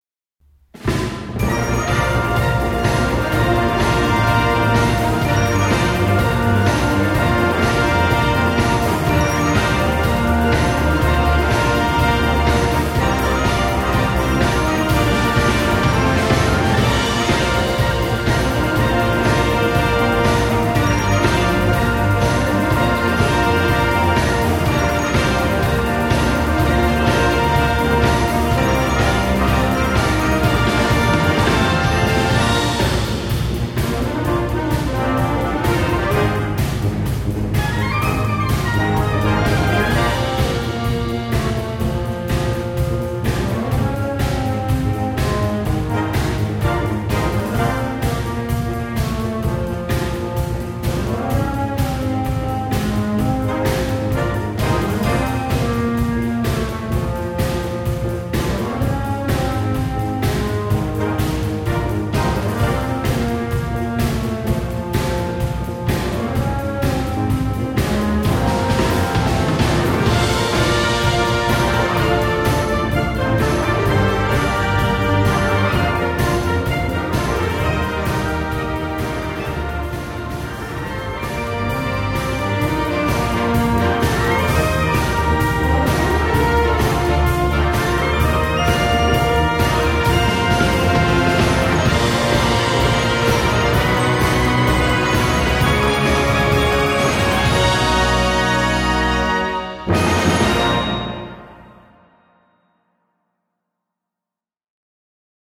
Gattung: Unterhaltungsmusik für Blasorchester
Besetzung: Blasorchester
Ein weiteres Juwel im Pop-Stil